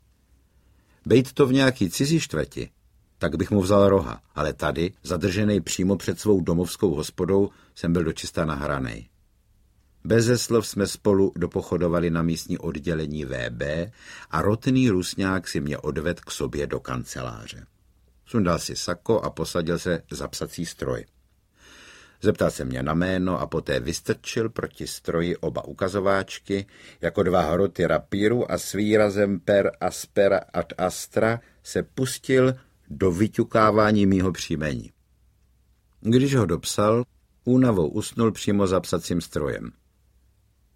Občanský průkaz audiokniha
Ukázka z knihy
• InterpretJaroslav Achab Haidler